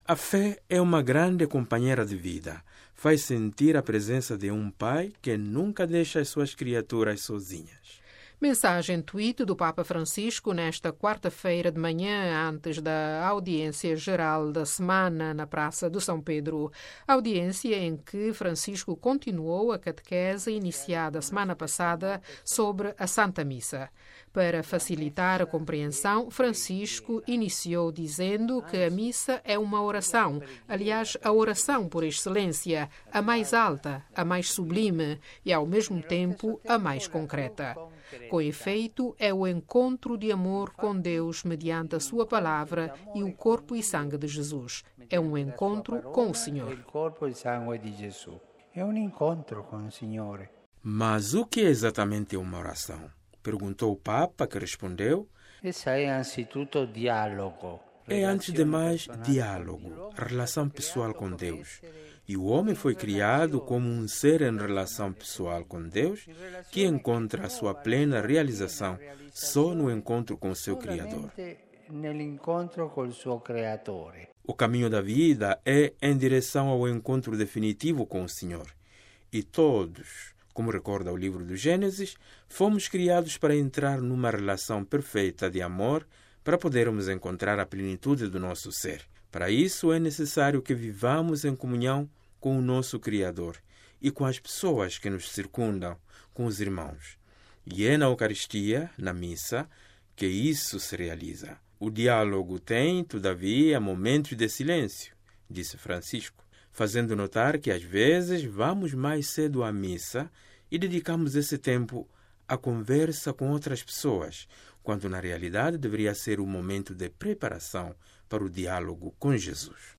A Missa é a oração por excelência - Papa na audiência geral